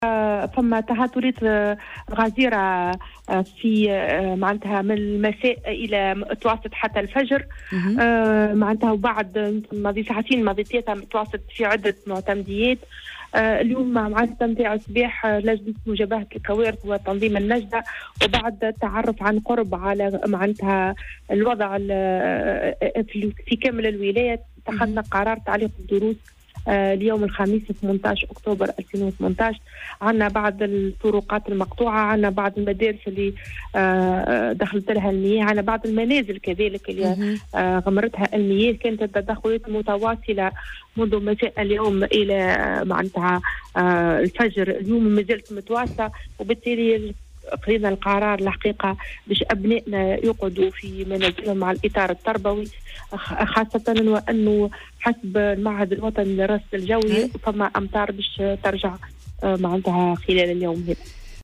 أعلنت والية نابل سلوى الخياري اليوم الخميس تعليق الدروس في ولاية نابل تحسّبا لهطول امطار غزيرة، في مداخلة هاتفية مع "الجوهرة أف ام" في برنامج "صباح الورد".